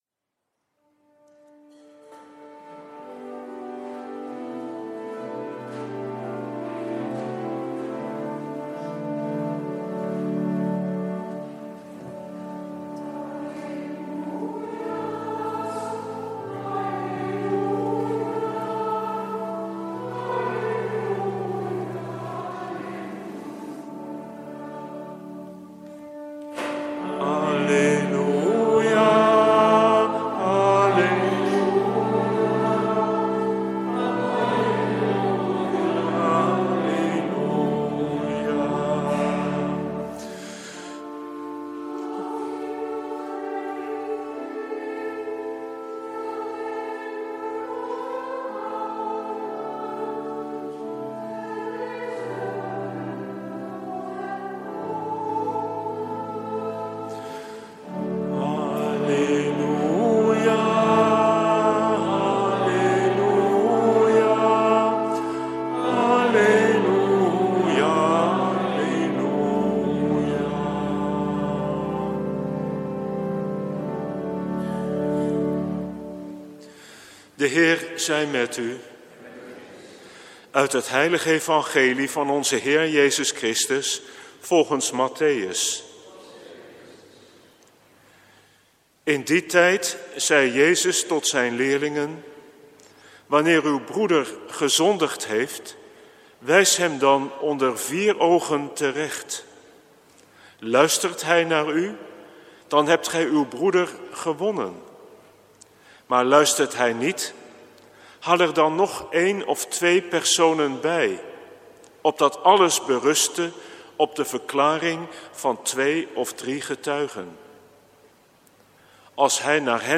Preek 23e zondag, door het jaar A, 6/7 september 2014 | Hagenpreken
Eucharistieviering in de parochie van de H. Augustinus, in de kerken van de H. Willibrordus (Wassenaar), en De Goede Herder (Wassenaar), om 19.00, 09.30 en 11.00 uur.